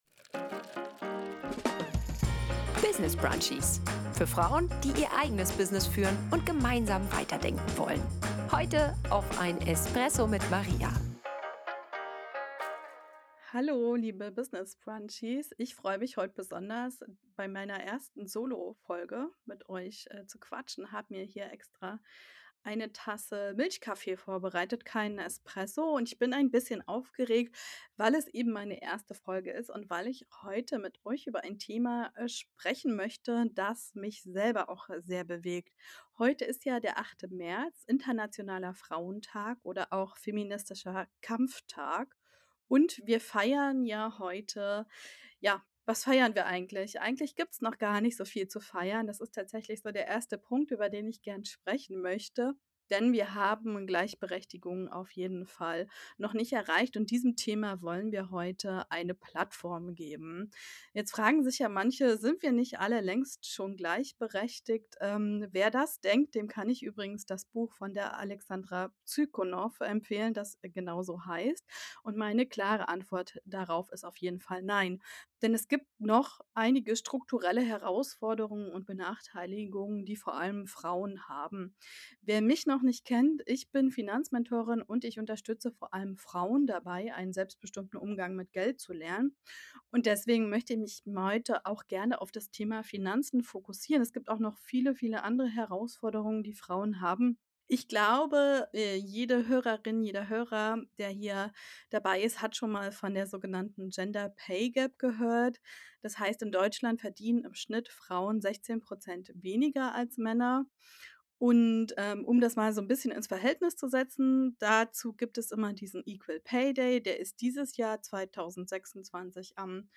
in dieser Solo-Folge